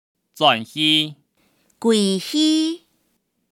Hong/Hakka_tts